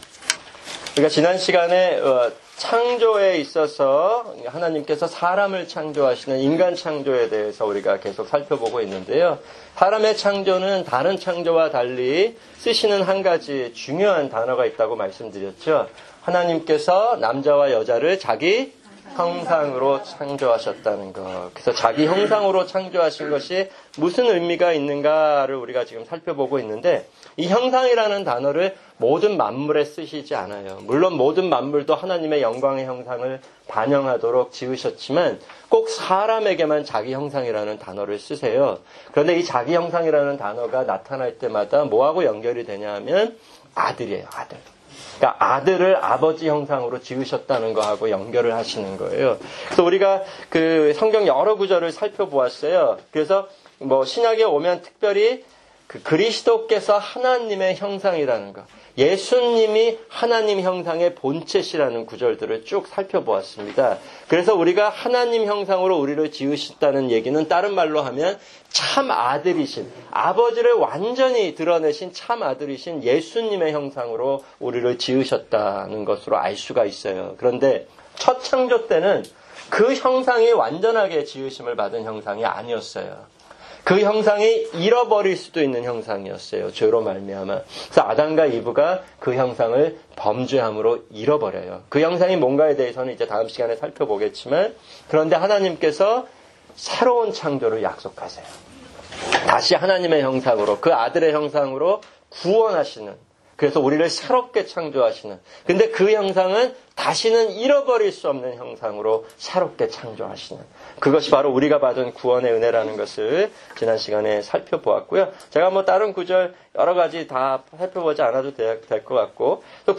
[주일 설교] 사도행전 9:1-22